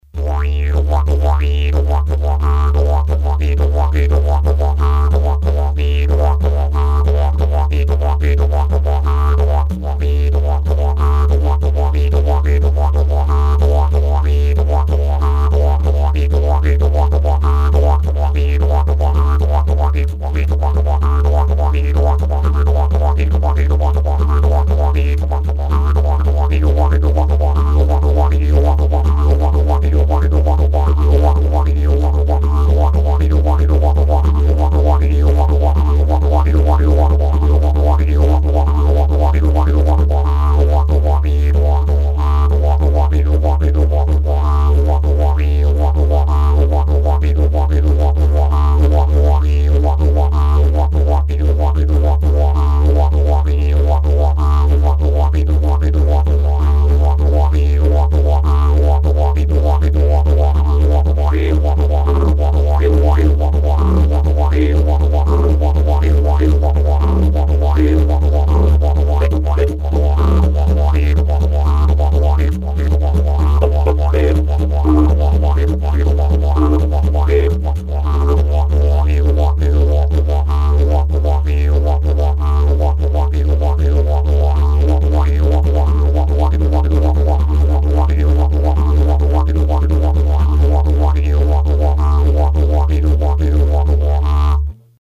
Sur ce rythme la je ne me sers absolument pas des joues, elles restent plaquées en permanence, respiration avec la langue et la machoire.